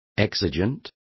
Complete with pronunciation of the translation of exigent.